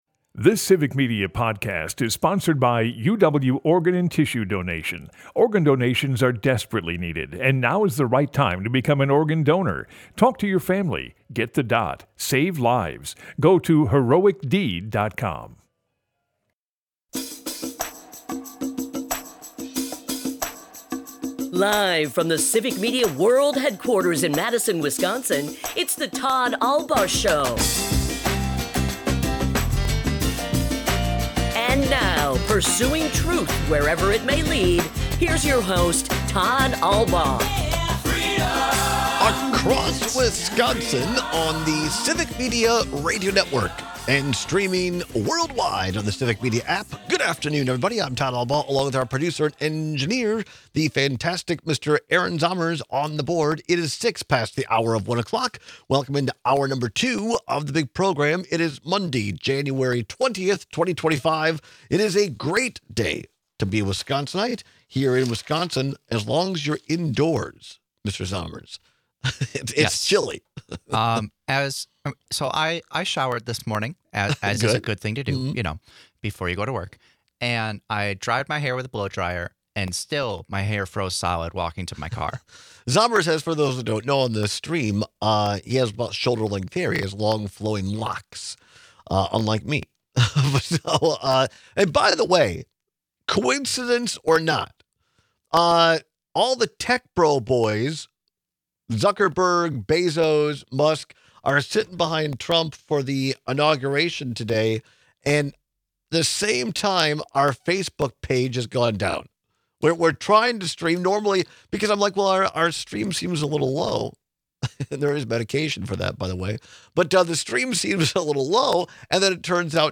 We take your calls and texts!